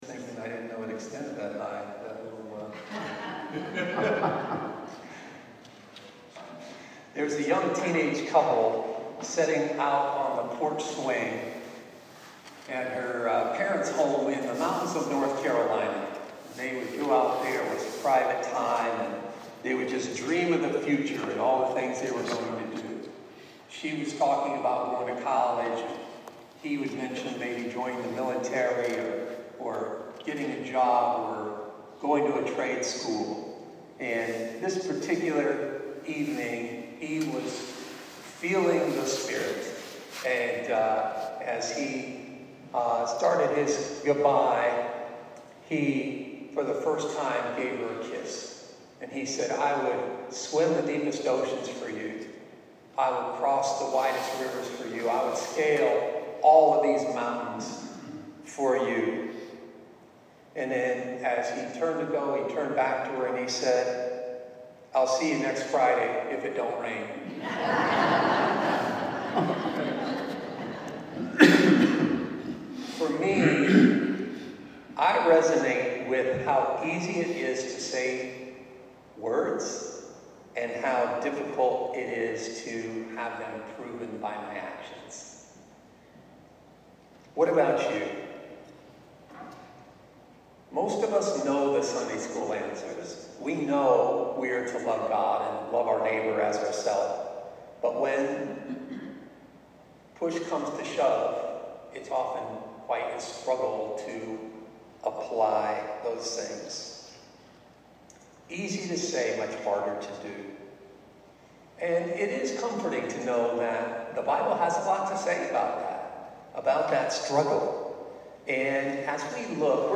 Sermon-14-Sept-25.mp3